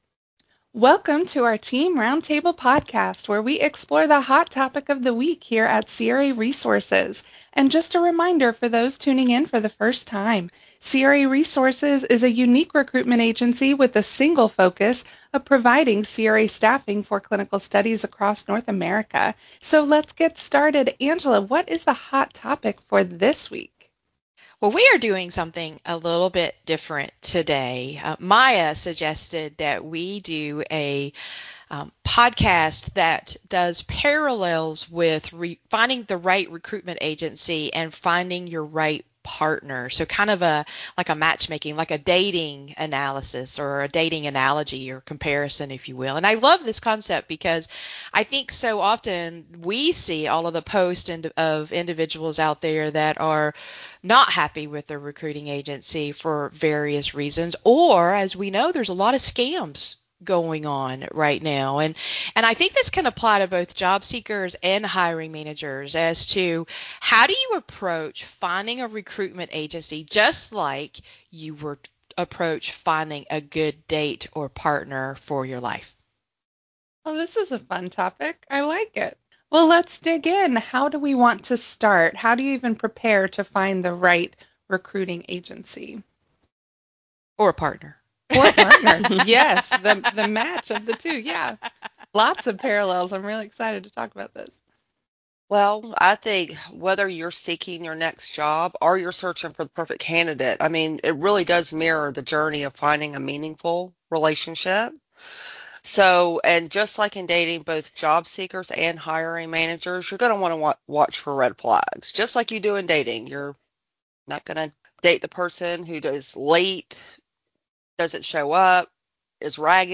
Roundtable: The Talent Match-Choosing the Right Recruiter - craresources